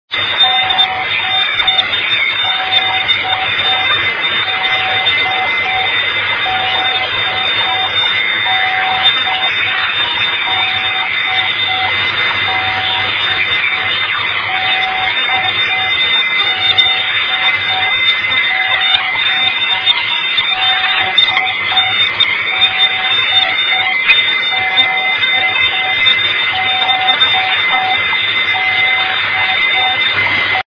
Designated as "MX" by Enigma 2000, these are single letter beacons which are used by the Russian navy to test the propagation.
Best reception is in CW mode, if your reciever doesen't have CW mode SSB works just as well.